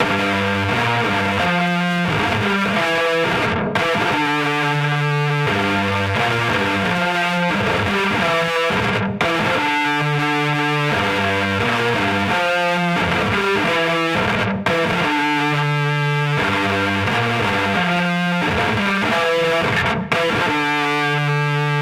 Metal Mayhem2 HEAVY BRIT DAMAGE 176 Gm
Tag: 176 bpm Heavy Metal Loops Guitar Electric Loops 3.67 MB wav Key : G Reason